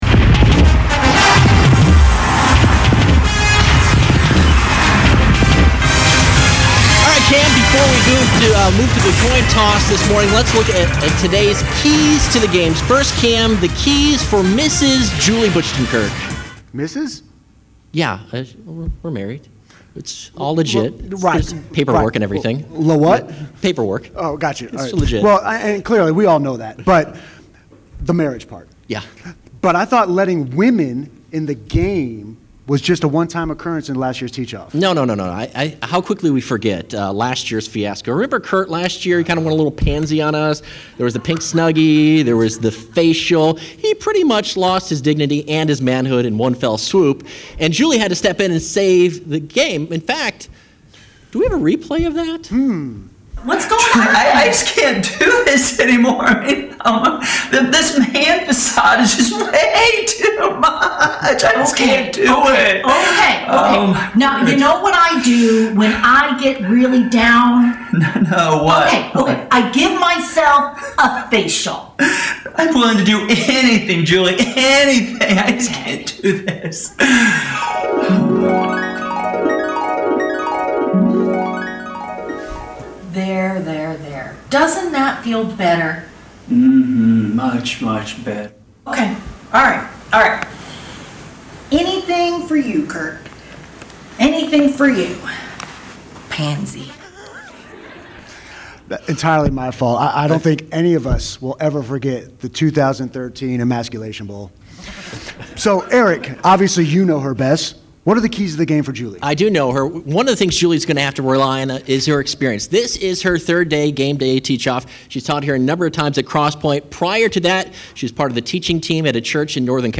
There will be a stadium environment, music you’ll know, a head-to-head teach-off competition including color commentary, a rocking half-time show, and maybe even a few surprises!